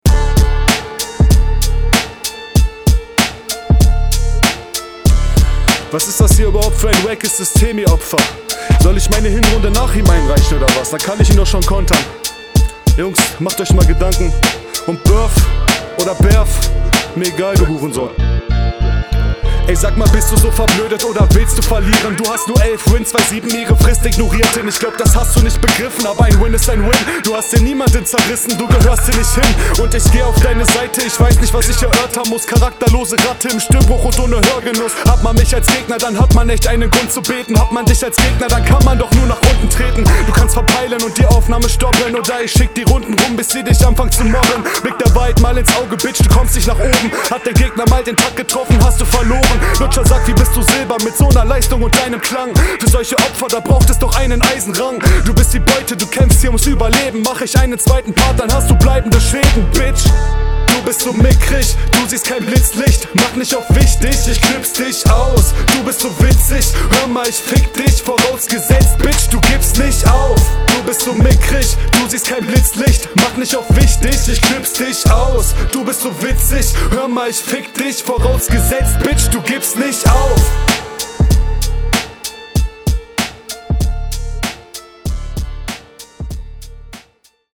Also dieses Hook Ding am Ende kommt leider echt nicht geil.